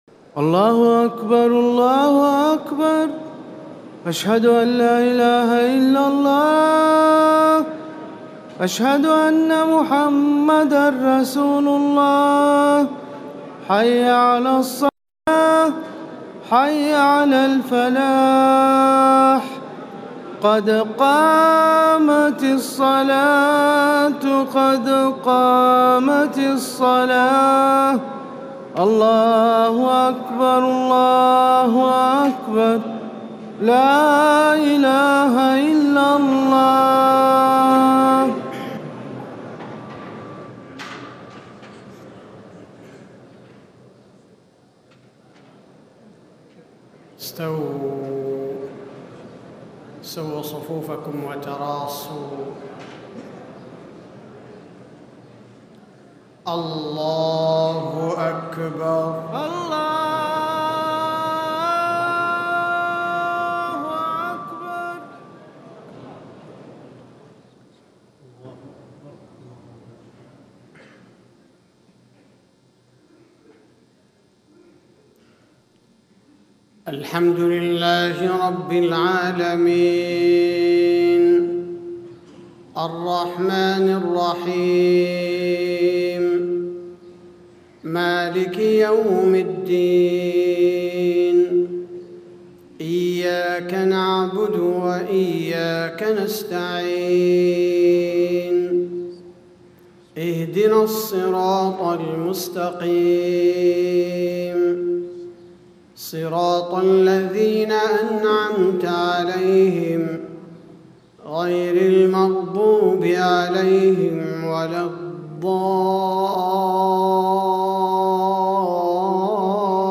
صلاة المغرب 1جمادى الأولى 1437هـ من سورة يونس 6-11 > 1437 🕌 > الفروض - تلاوات الحرمين